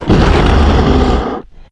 c_anusibath_hit3.wav